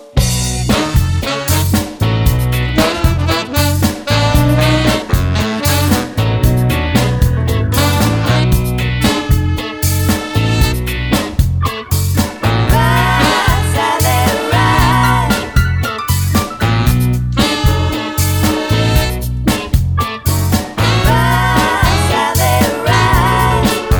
Medley - One Semitone Down Soundtracks 5:49 Buy £1.50